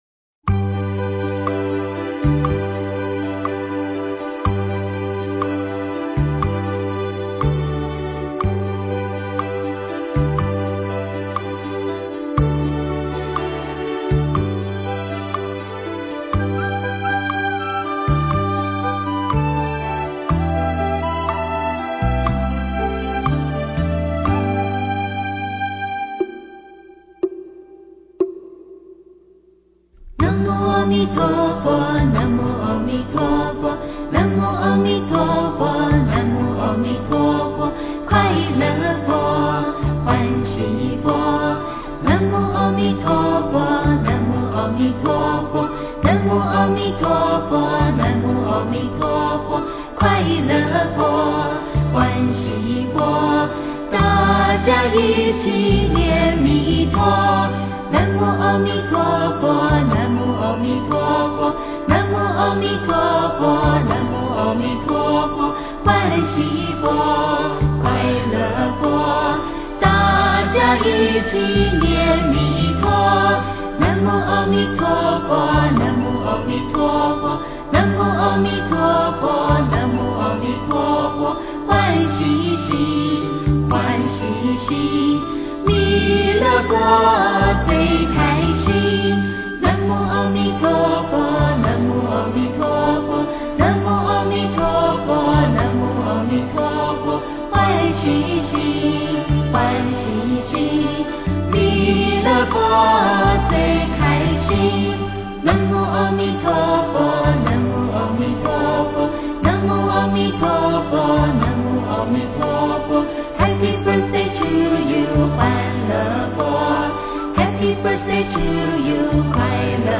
佛音 诵经 佛教音乐 返回列表 上一篇： 毗庐佛咒 下一篇： 三宝歌 相关文章 寒鸭戏水--未知 寒鸭戏水--未知...